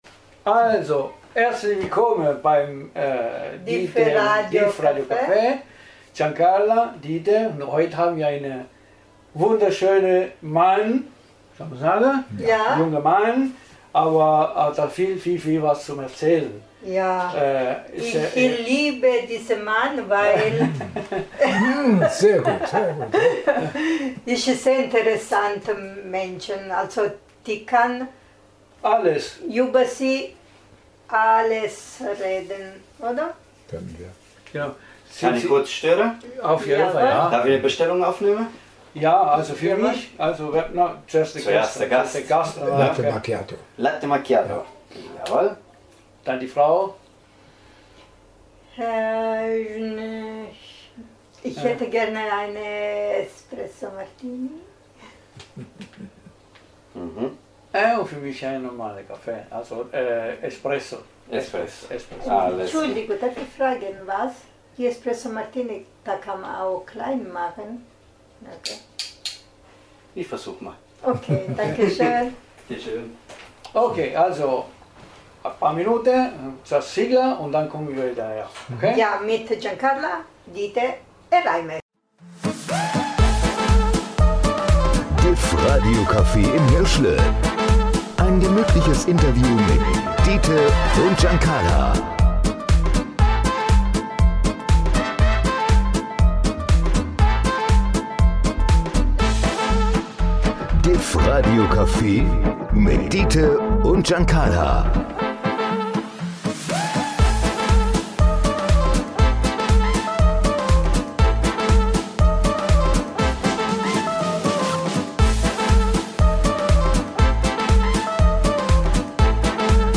im Hirschraum vom Diff Radio Café im Hirschle in Seebronn.